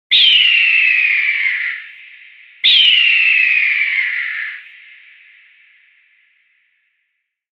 Cinematic-eagle-screech-sound-effect.mp3